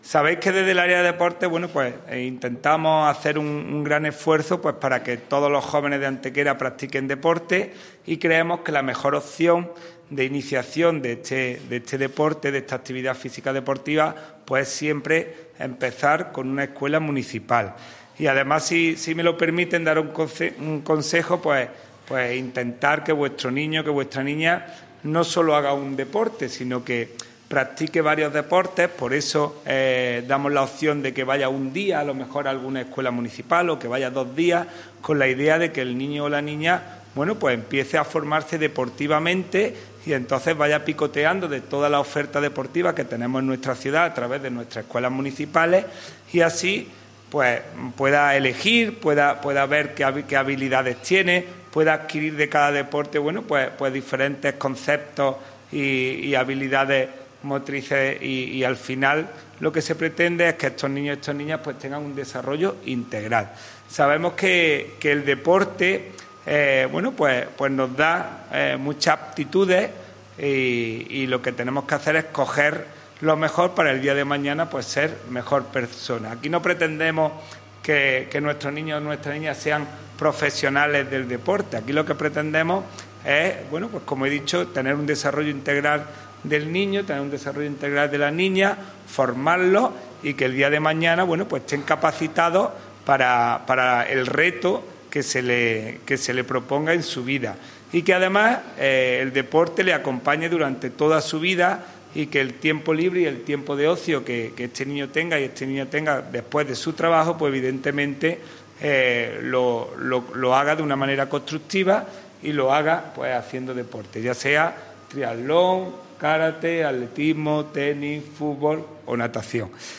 El teniente de alcalde delegado de Deportes, Juan Rosas, ha presentado hoy jueves en rueda de prensa la primera parte de las escuelas deportivas municipales que compondrán la oferta al respecto del Área de Deportes del Ayuntamiento de Antequera.
Cortes de voz